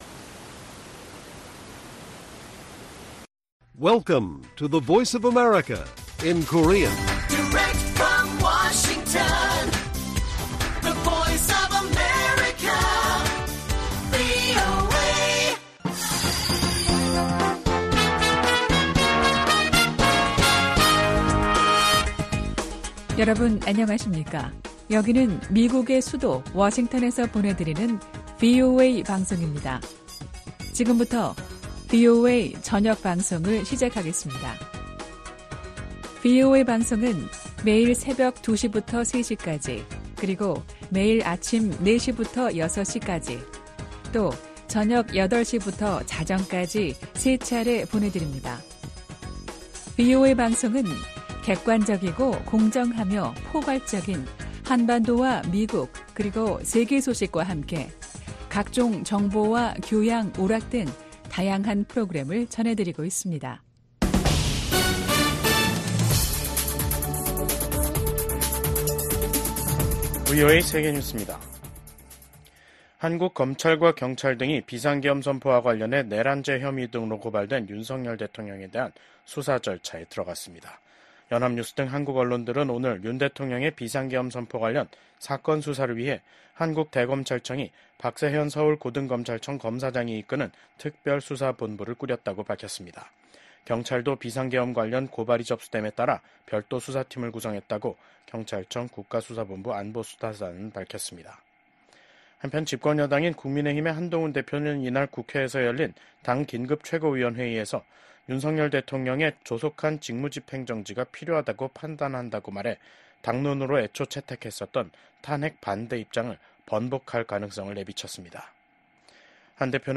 VOA 한국어 간판 뉴스 프로그램 '뉴스 투데이', 2024년 12월 6일 1부 방송입니다. 윤석열 대통령 탄핵소추안에 대한 국회 표결을 하루 앞두고 한국 내 정국은 최고조의 긴장으로 치닫고 있습니다. 미국 국무부는 한국이 대통령 탄핵 절차에 돌입한 것과 관련해 한국의 법치와 민주주의를 계속 지지할 것이라고 밝혔습니다. 한국의 계엄 사태와 관련해 주한미군 태세에는 변함이 없다고 미국 국방부가 강조했습니다.